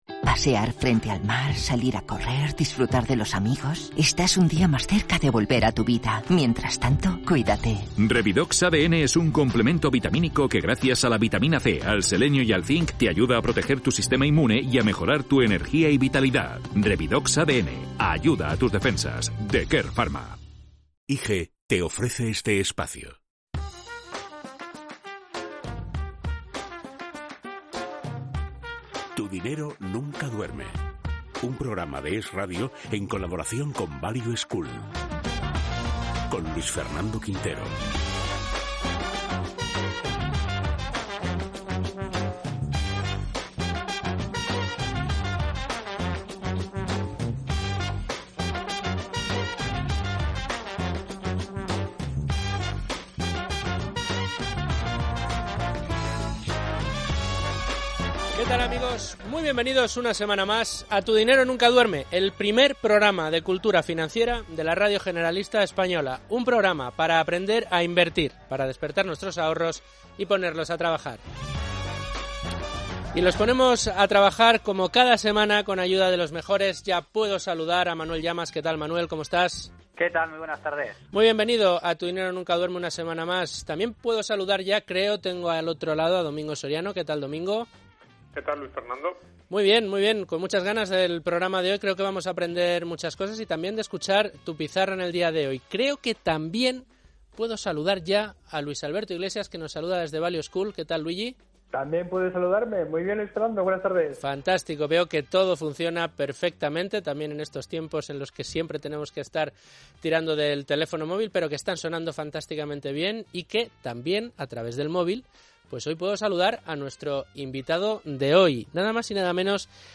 No te puedes perder la Tertulia